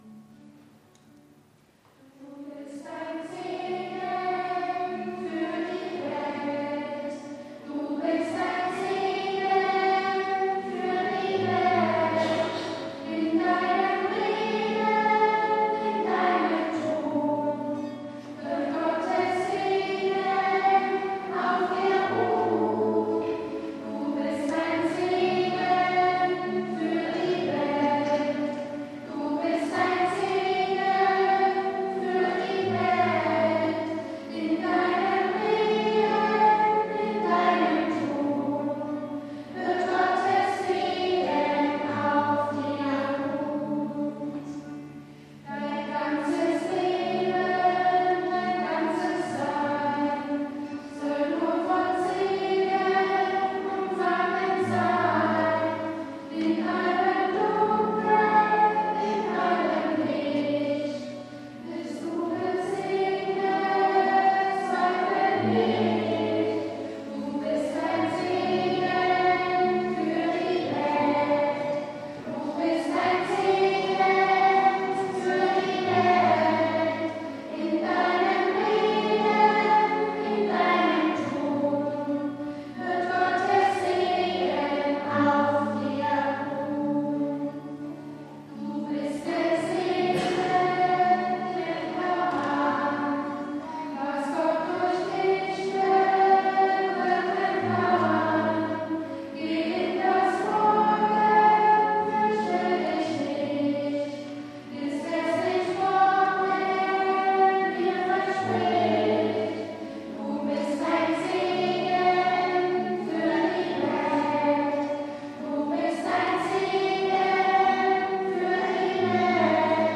Du bist ein Segen ... Kinderchor der Ev.-Luth. St. Johannesgemeinde Zwickau-Planitz
Audiomitschnitt unseres Gottesdienstes am 11.Sonntag nach Trinitatis 2024